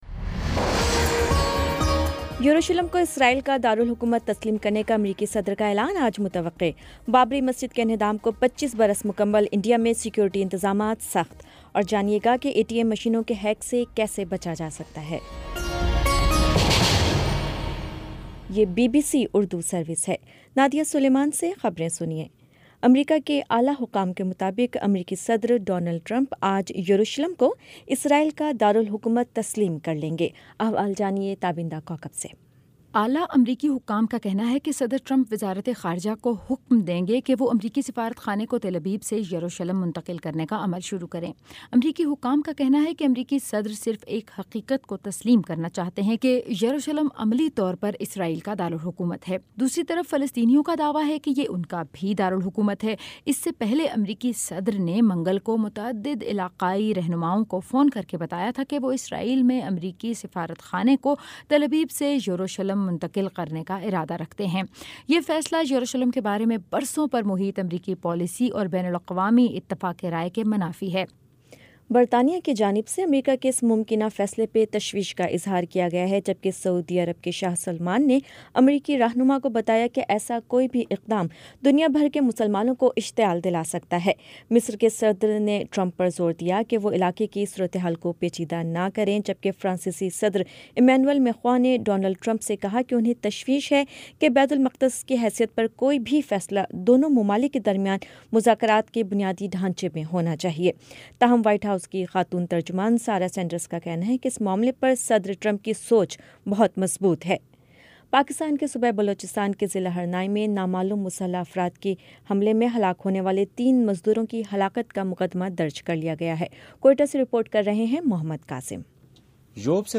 دسمبر 06 : شام پانچ بجے کا نیوز بُلیٹن